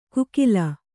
♪ kukila